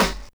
Snare (29).wav